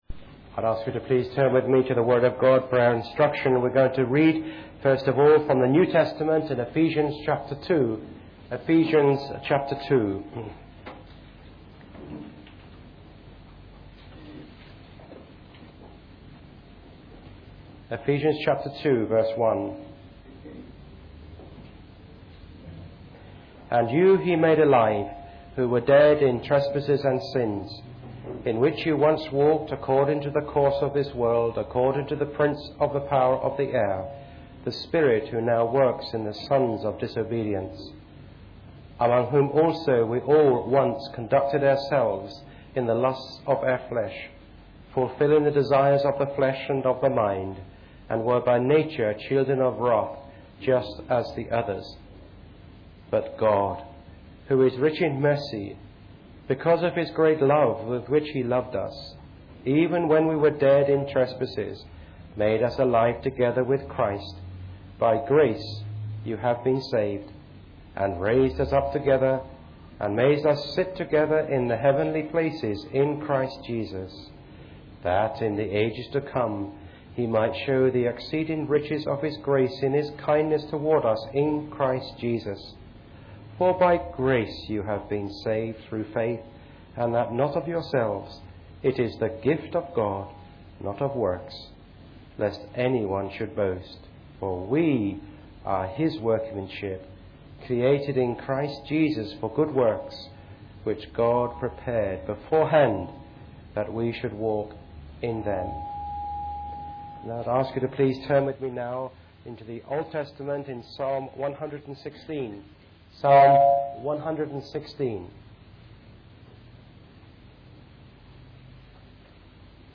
Preached on the 2nd of September 2012.